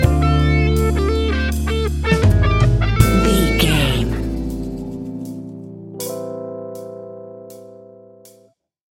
Epic / Action
Fast paced
In-crescendo
Uplifting
Ionian/Major
A♭
hip hop